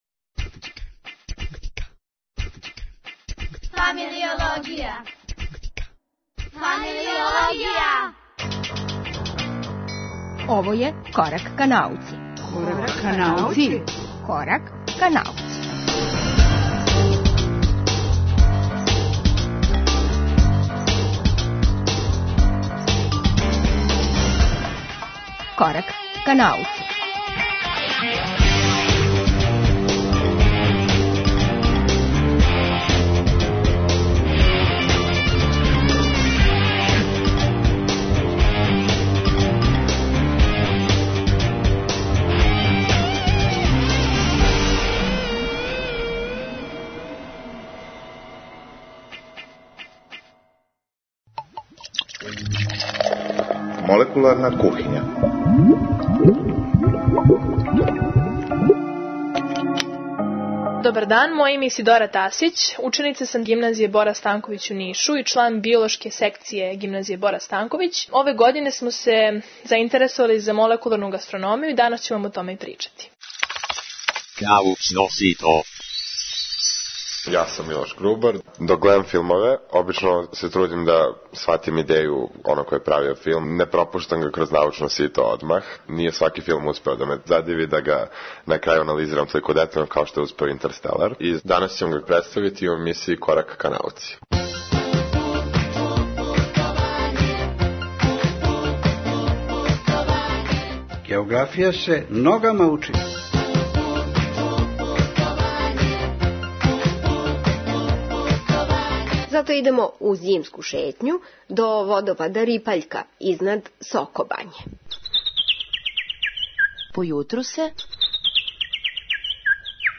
Географија се ногама учи - радио путопис (запис са водопада Рипаљка код Сокобање)